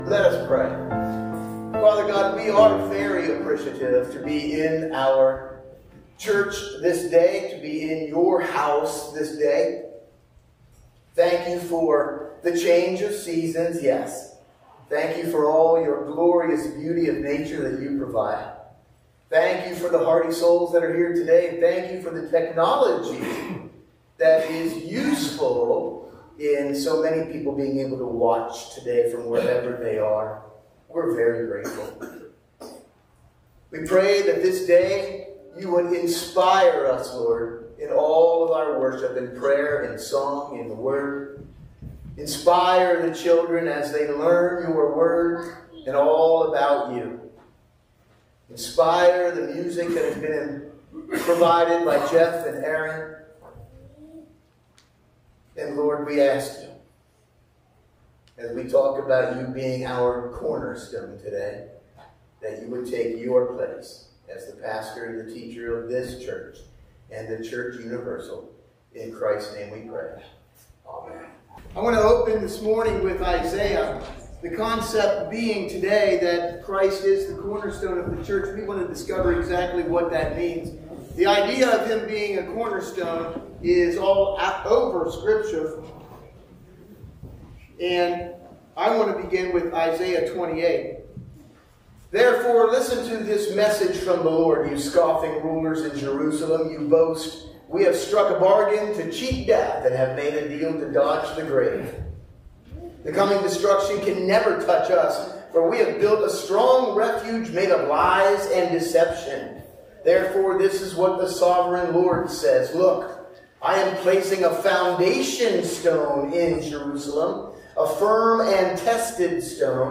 Sunday Morning Service – January 19, 2025